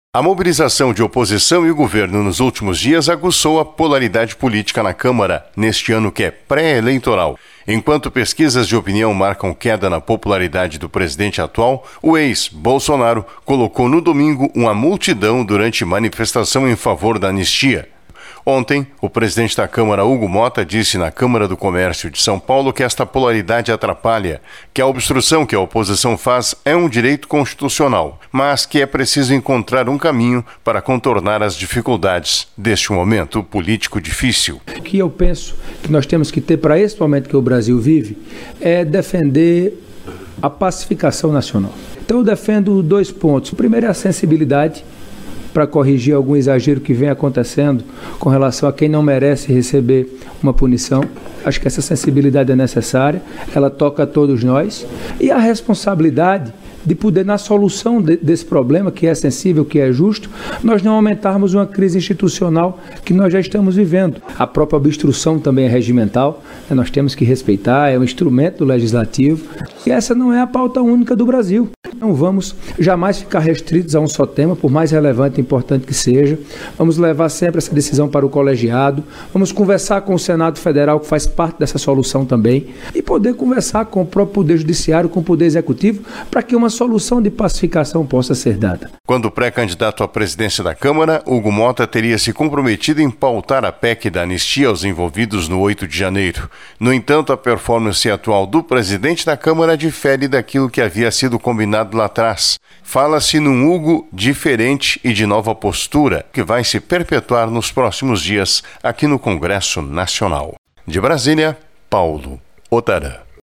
Hugo Motta diz durante encontro em São Paulo que Anistia não é a principal pauta do país